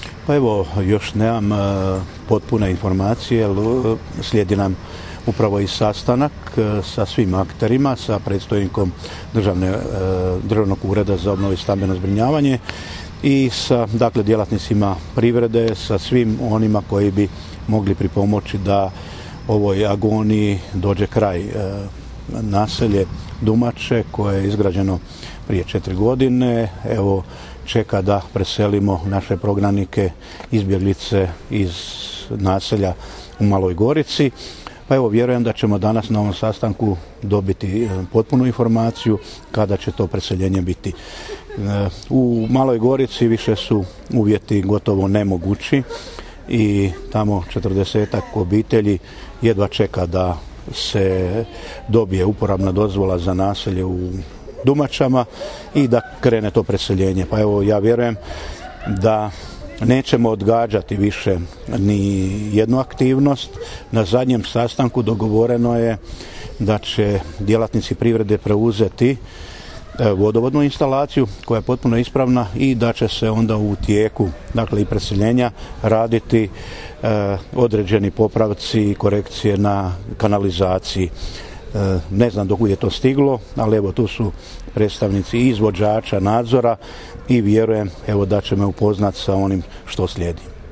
Poslušajte što je o ovoj temi neposredno prije sastanka izjavio župan Ivo Žinić.